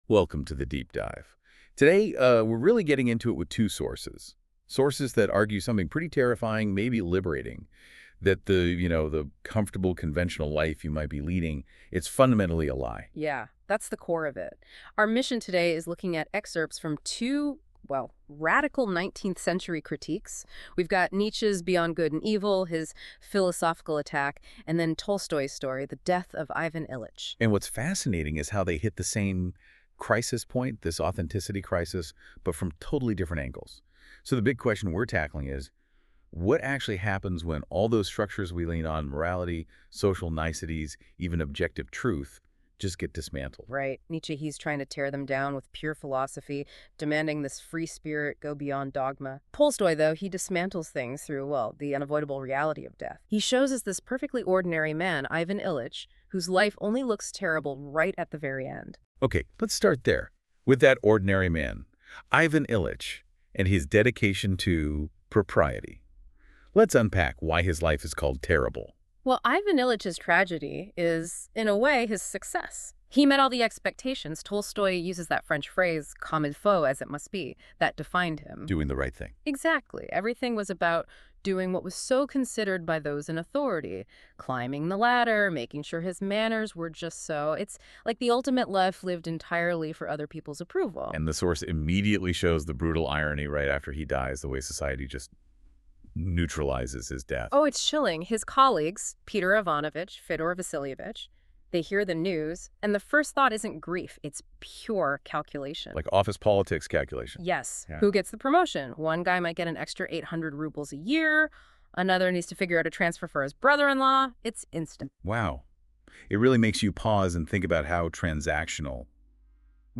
One of NotebookLM’s most touted features is its ability to produce a podcast based on the study materials. I tried that as well, and it generated an insightful discussion comparing the themes of both books.
nietzsche-tolstoy-notebooklm.m4a